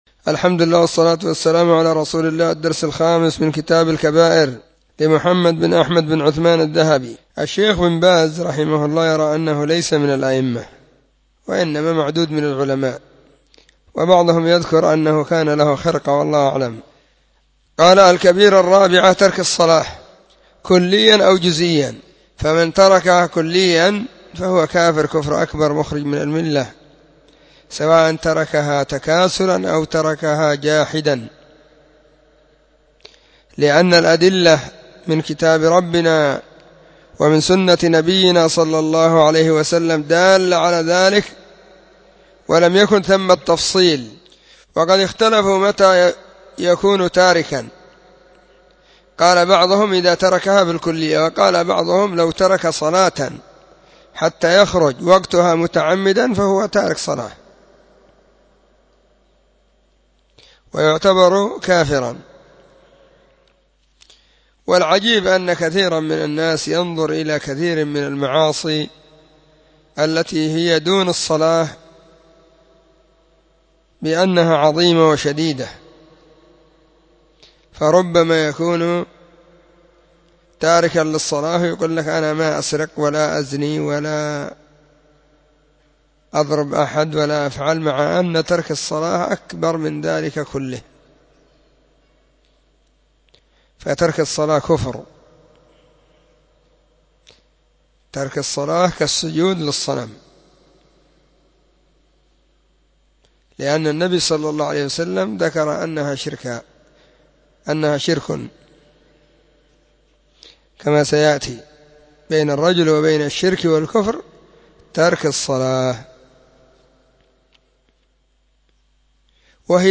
🕐 [بين مغرب وعشاء – الدرس الثاني]
📢 مسجد الصحابة – بالغيضة – المهرة، اليمن حرسها الله.